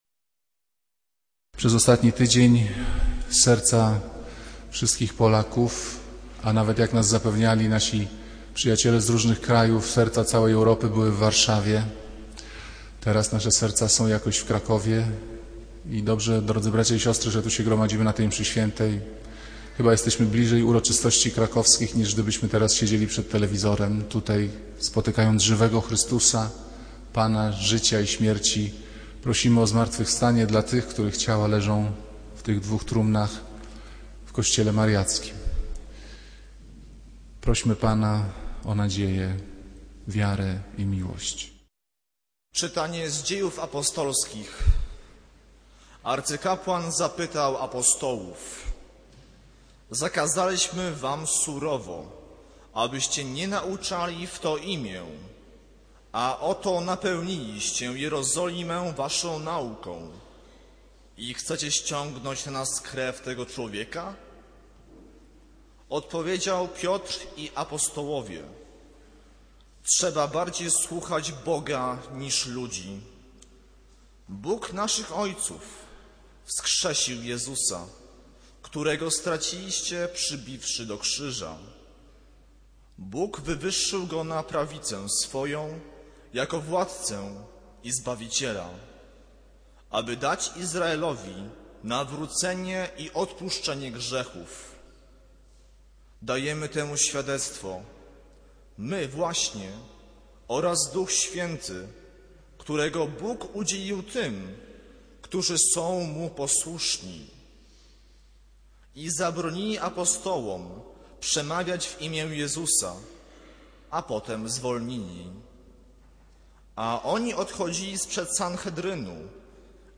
Kazanie z 18 kwietnia 2010 r.